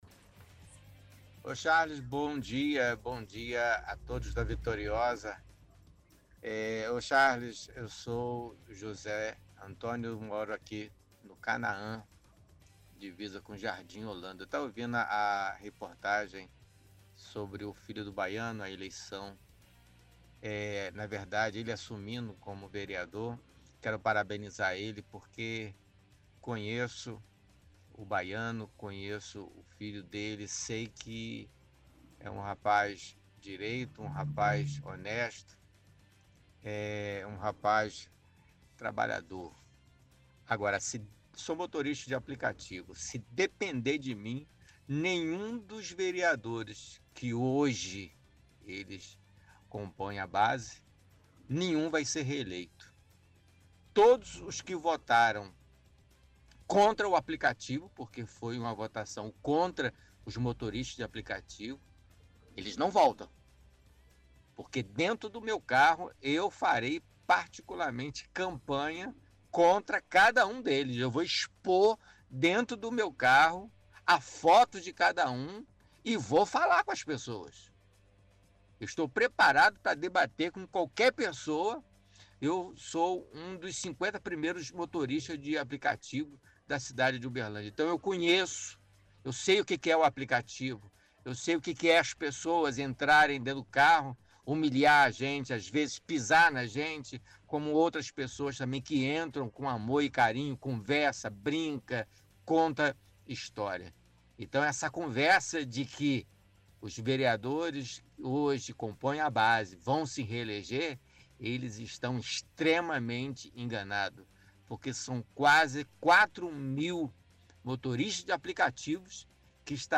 – Ouvinte fala sobre o novo vereador Jair Ferraz, elogia sua atuação. Fala que sendo motorista de aplicativo, fará campanha contra os outros vereadores que votaram a favor do projeto de regulamentação dos motoristas de aplicativo.